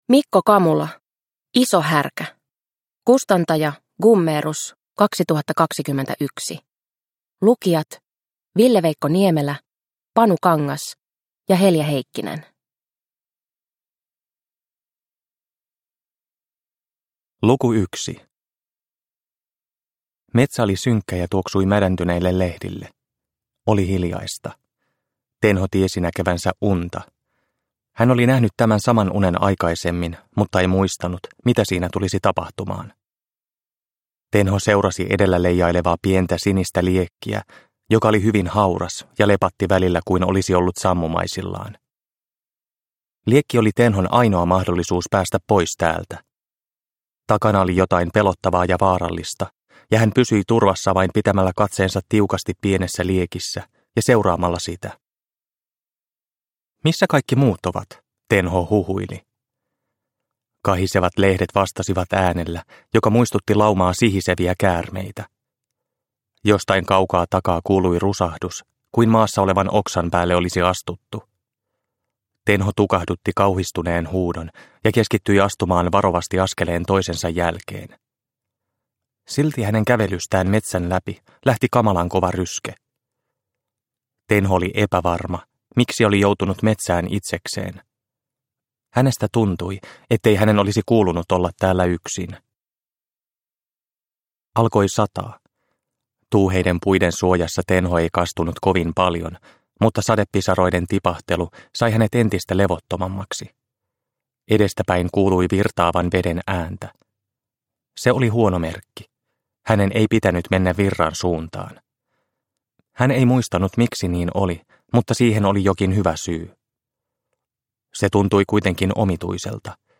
Iso härkä – Ljudbok – Laddas ner